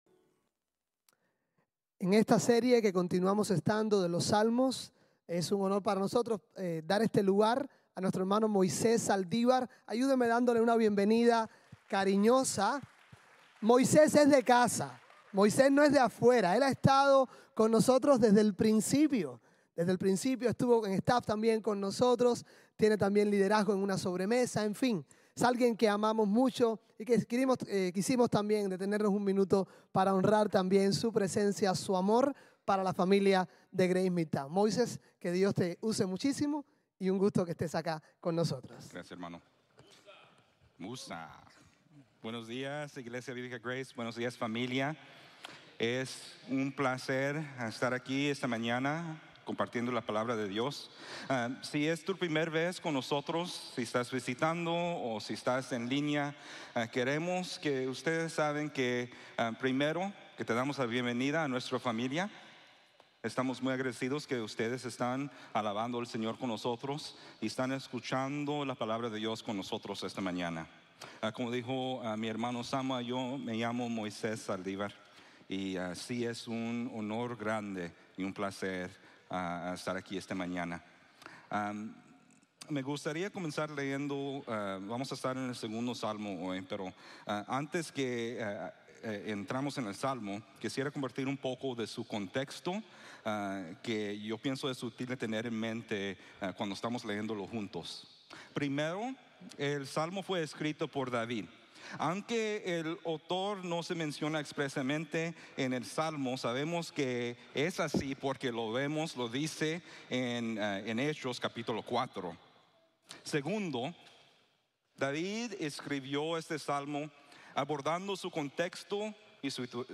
Las Naciones se Enfrentan Pero Dios Reina | Sermón | Grace Bible Church